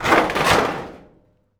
metal_sheet_impacts_06.wav